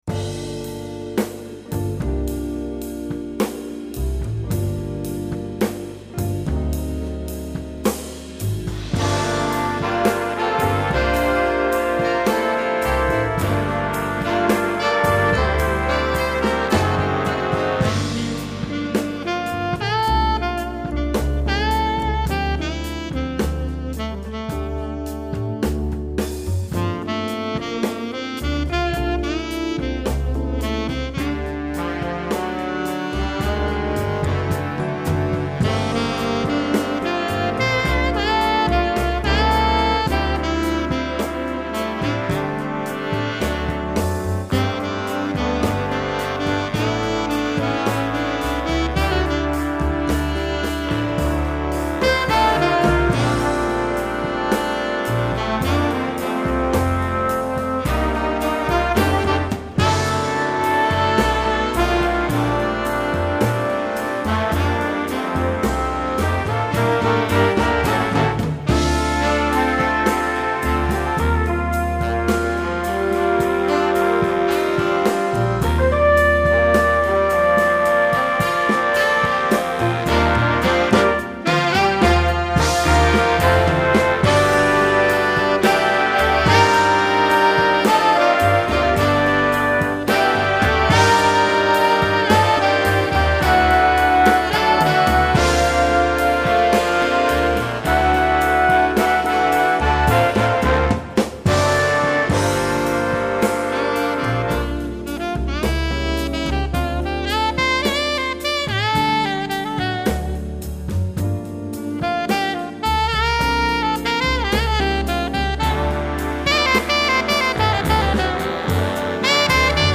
Voicing: Alto Saxophone w/BB